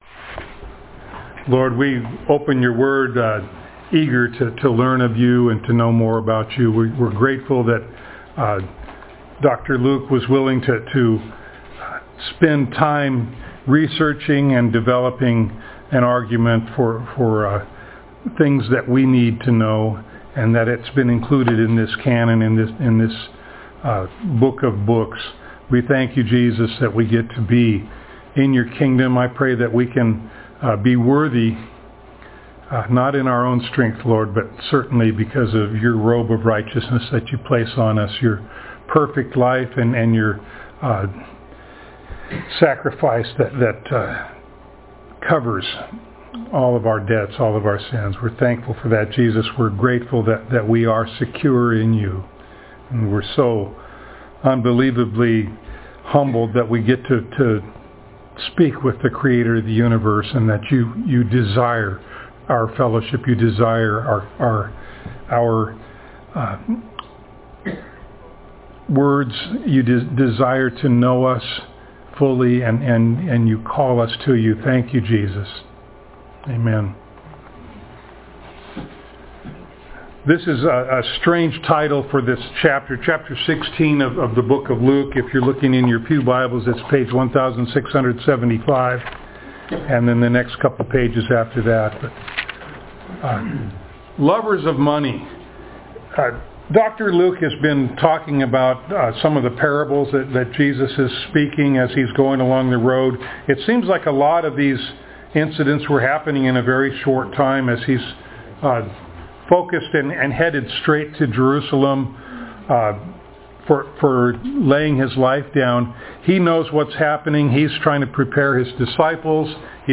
Luke Passage: Luke 16:1-31 Service Type: Sunday Morning Download Files Notes « Rejoicing in Heaven Over Repentant Sinners Stumbling Blocks